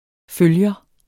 Udtale [ ˈføljʌ ]